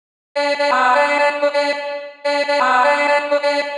• chopped vocals 109-127 female - Bm - 128.wav